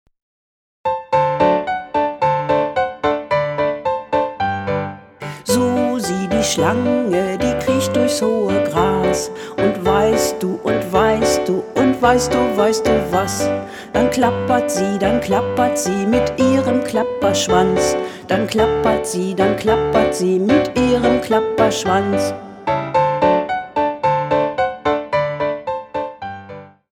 Das Lied
laut und leise, langsam und schnell.